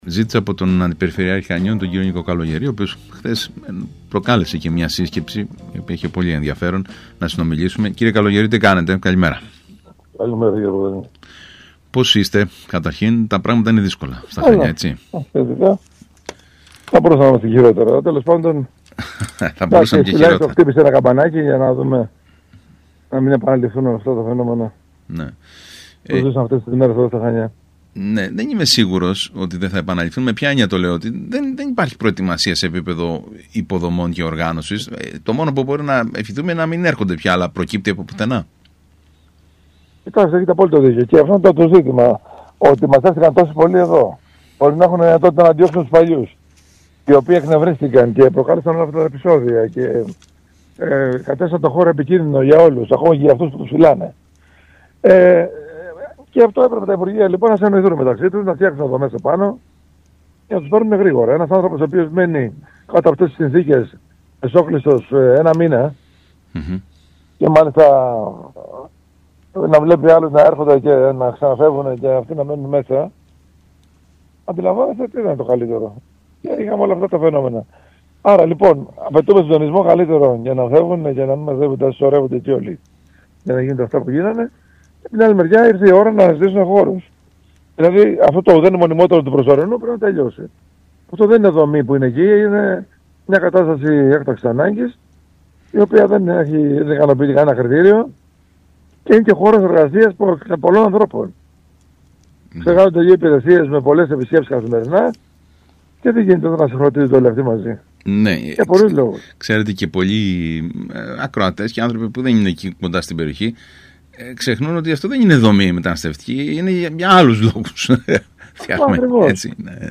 Ο κ. Καλογερής μιλώντας στον ΣΚΑΙ Κρήτης επανέλαβε τα όσα τραγικά συμβαίνουν στην Αγυιά και ξεκαθάρισε ότι εκεί μόνο χώρος για μετανάστες δεν είναι.